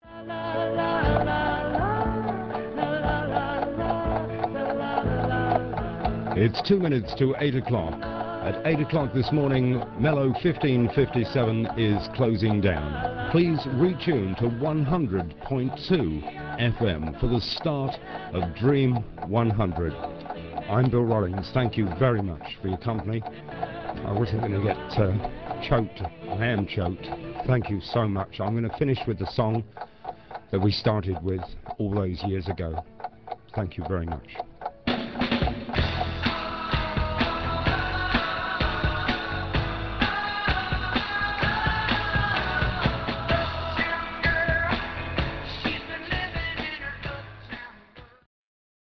closedown message